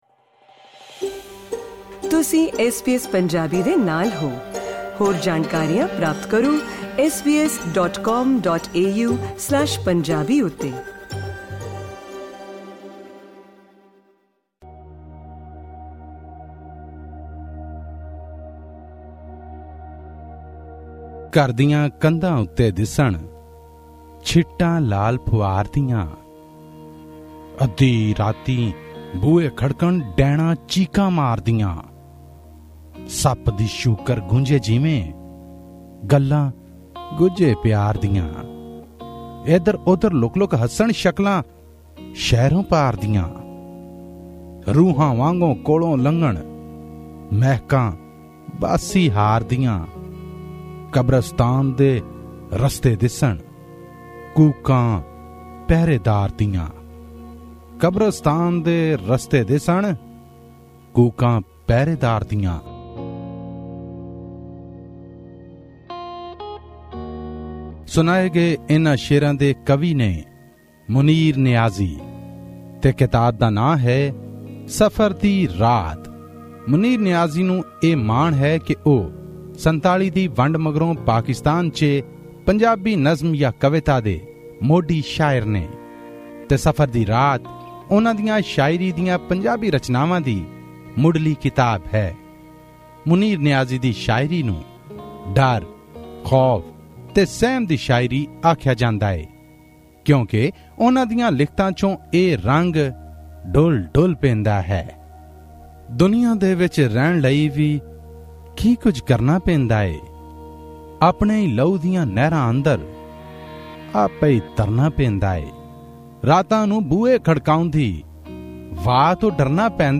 Pakistani Punjabi poetry book review: 'Safar Dee Raat' by Munir Niazi